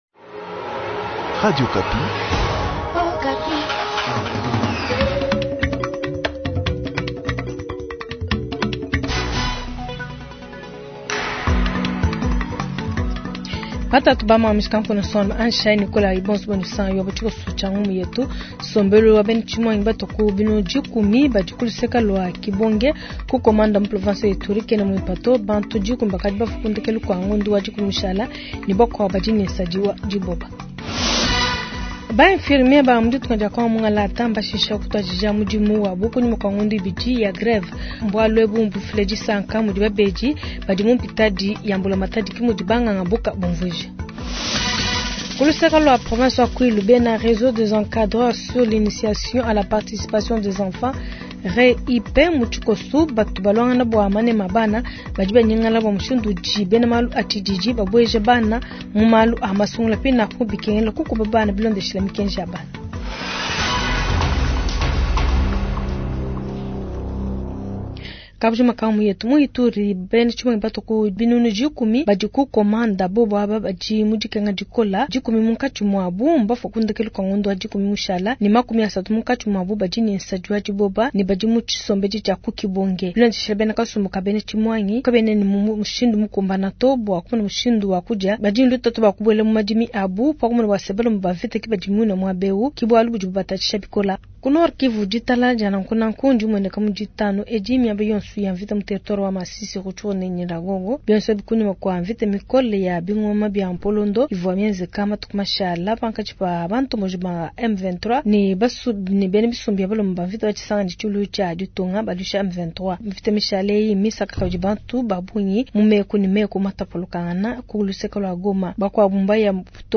Journal soir
• Bunia : Reportage dans le site des déplacés de Kibonge dans Komanda
• Kin : Assemblée Nationale interpellation du premier ministre l’auteur JB Kasweka s’exprime